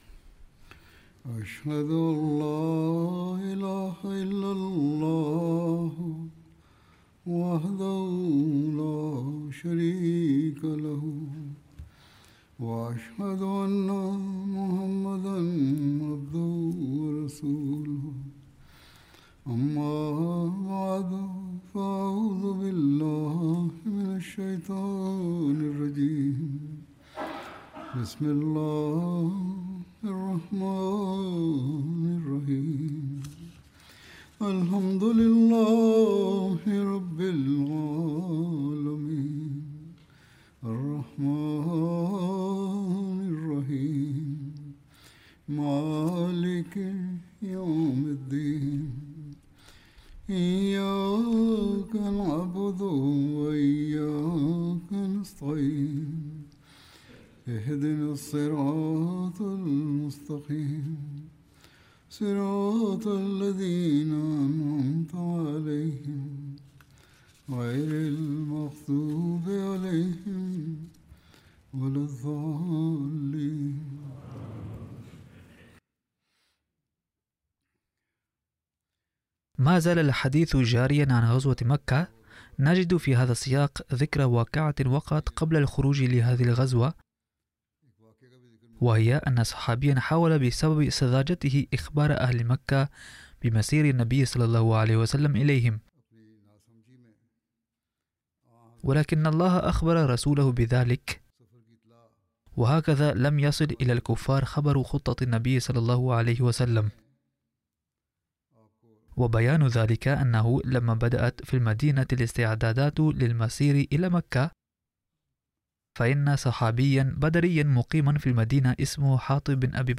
Arabic Translation of Friday Sermon delivered by Khalifatul Masih